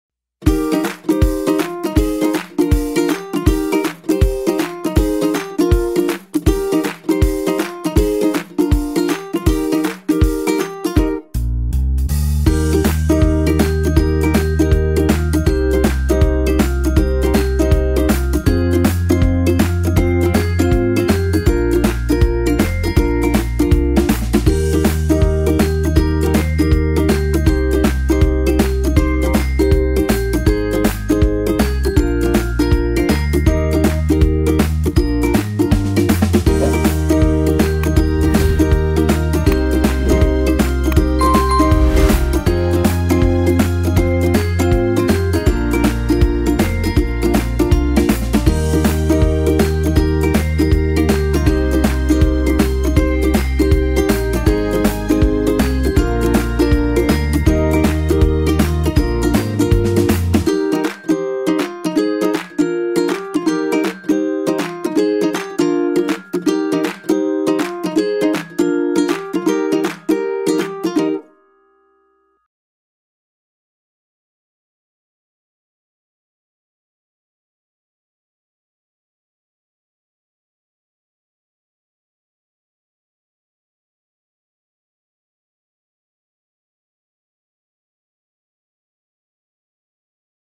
jugando_ninhos.mp3